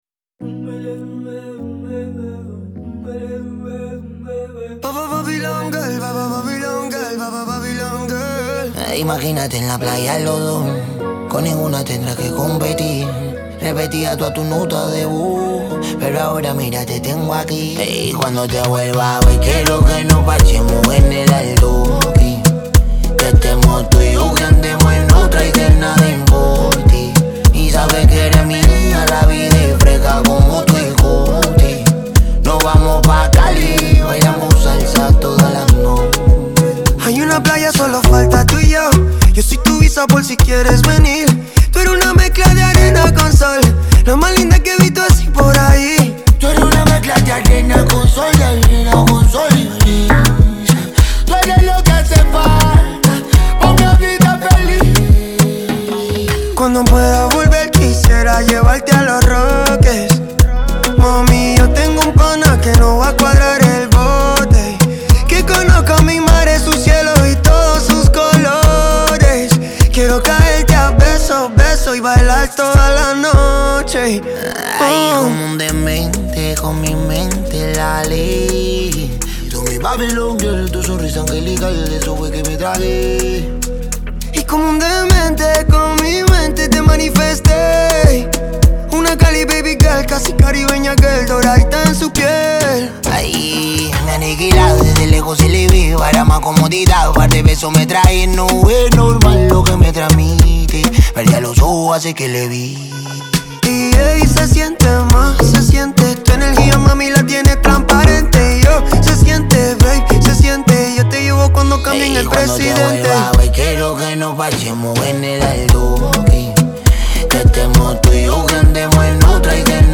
Genre: Latin.